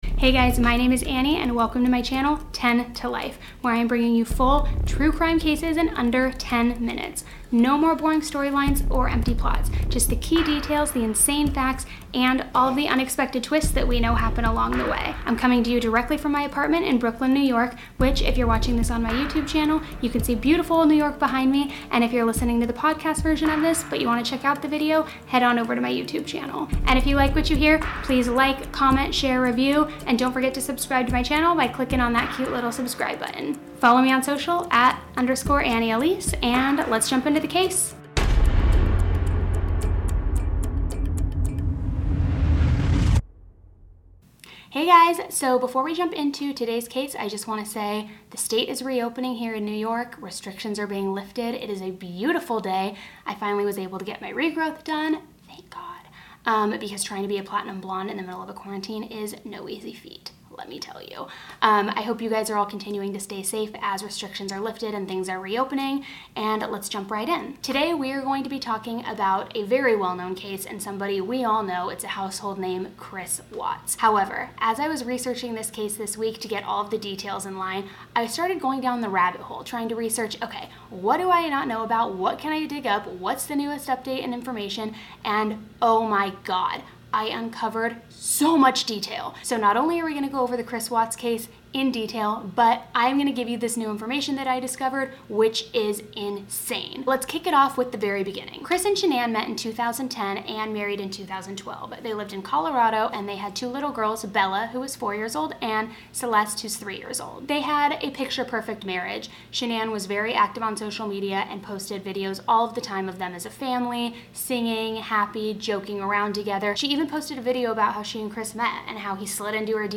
Even better, you’ll hear the story the way you’d want to: like your best friend is filling you in.